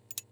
BellRingOverdone.wav